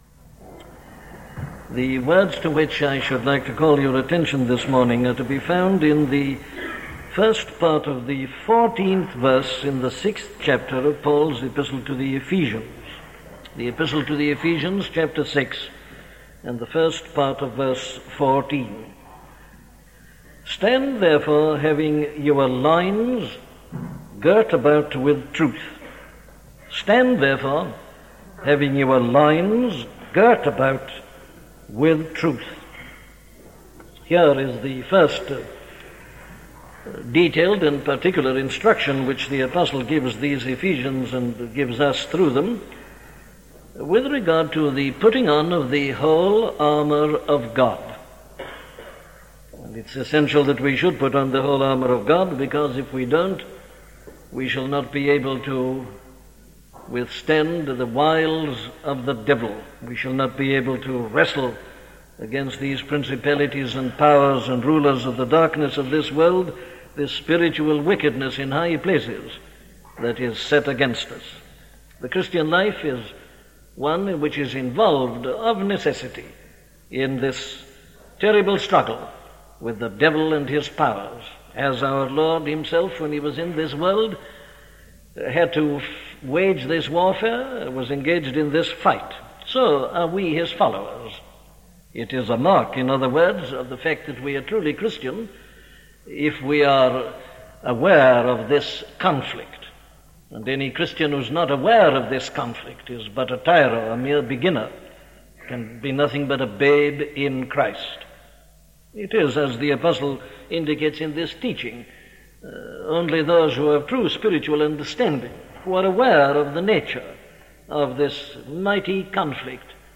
The Truth Revealed in Scripture - a sermon from Dr. Martyn Lloyd Jones
Listen to the sermon on Ephesians 6:14 'The Truth Revealed in Scripture' by Dr. Martyn Lloyd-Jones